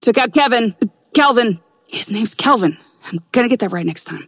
McGinnis voice line - Took out Kevin- Kelvin. His name's Kelvin. I'm gonna get that right next time.